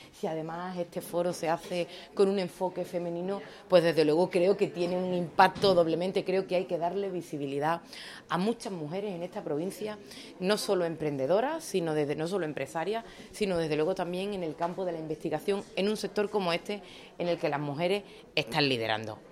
La responsable de Empleo en funciones ha participado en la apertura del I Foro de Mujeres Líderes en la Economía Azul
Ana-Carrera-apertura-foro-mp3.mp3